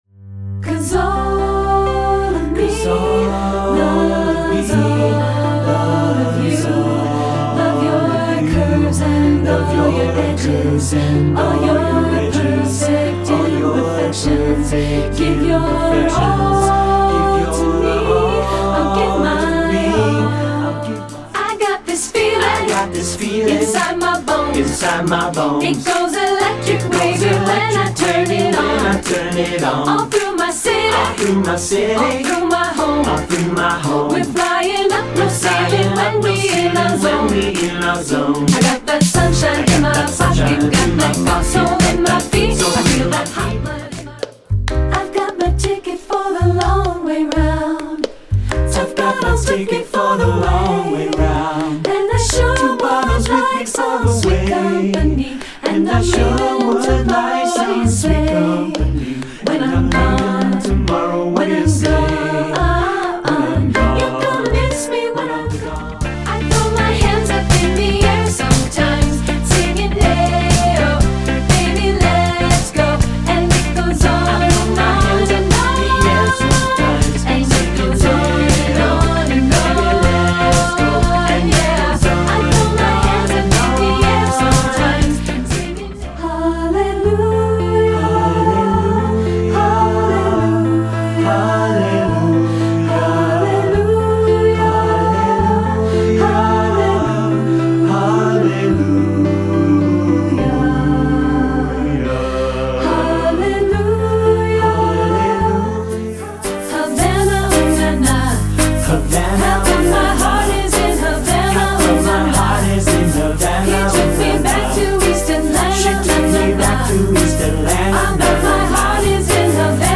Choral Collections